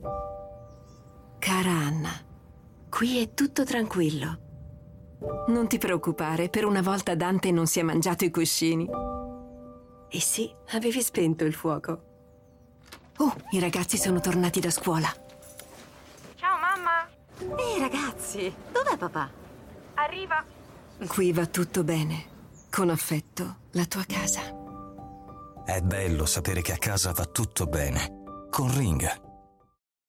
Caldo - Morbido